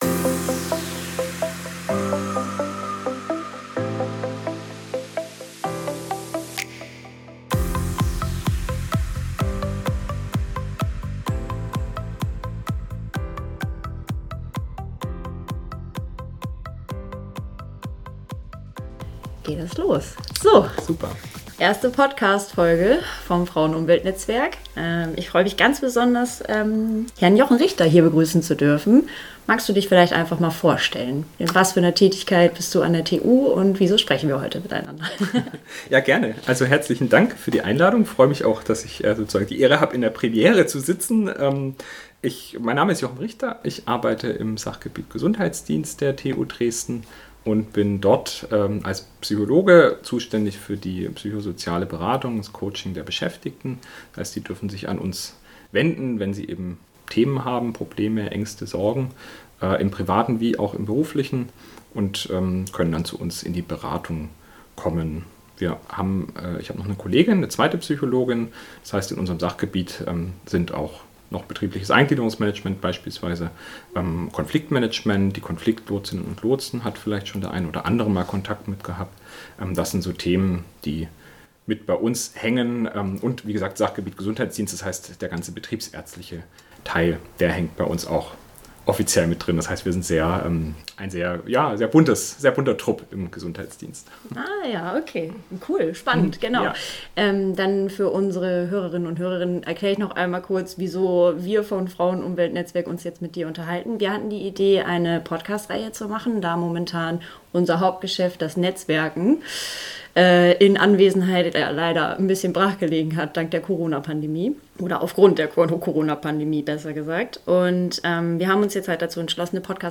Austausch zwischen Frauenumweltnetzwerk und Psychologischer Beratung - Über häufigeres Pausenmachen, Einsamkeit und viele weitere Themen